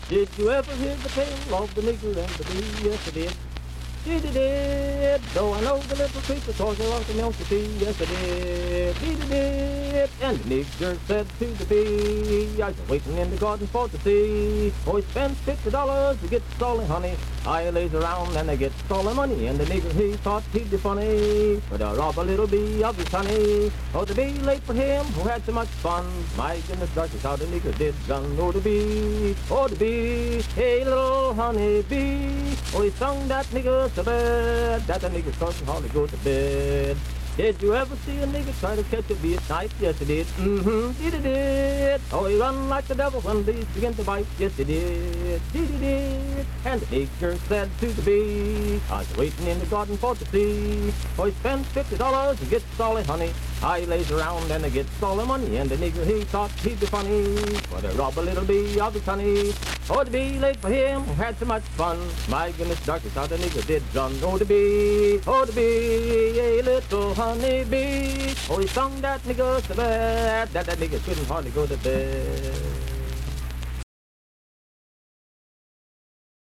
Unaccompanied vocal performance
Minstrel, Blackface, and African-American Songs
Voice (sung)